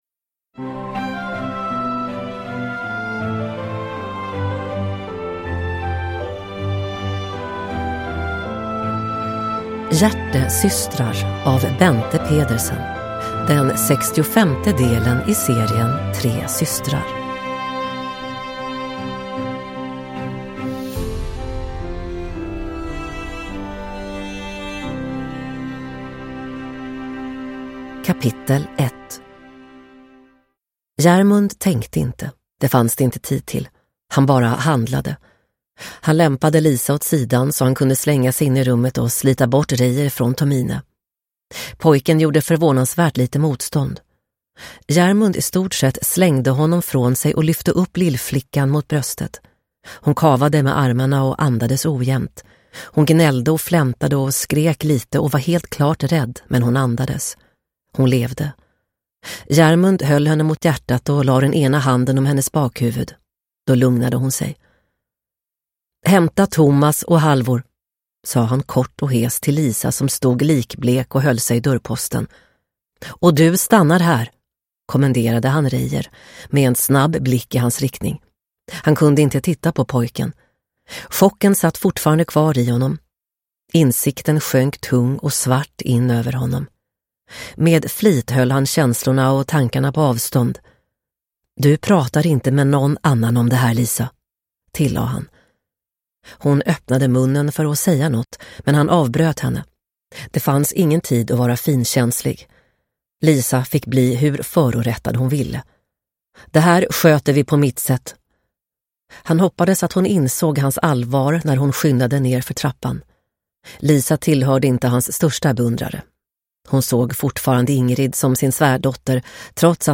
Hjärtesystrar – Ljudbok – Laddas ner